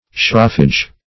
Meaning of shroffage. shroffage synonyms, pronunciation, spelling and more from Free Dictionary.